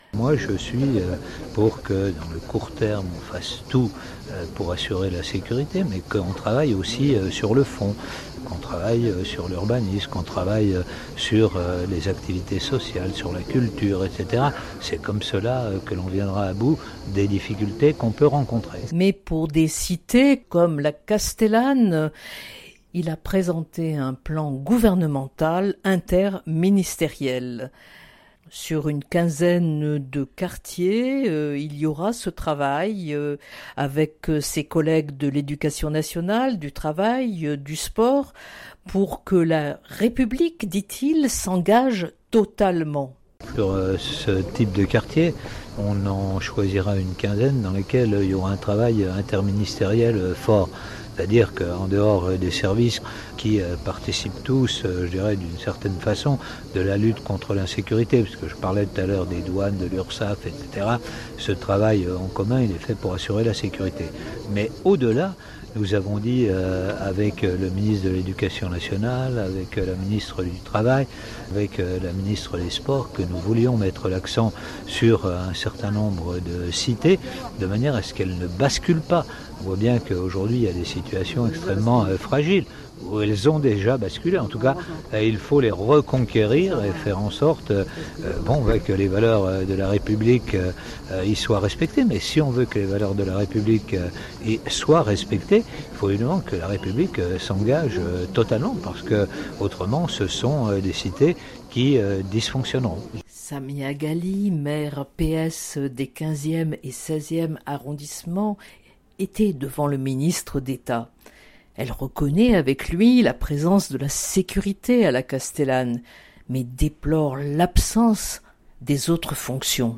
collomb_discours_extraits_securite_1_5_07_2018.mp3